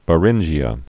(bə-rĭnjē-ə)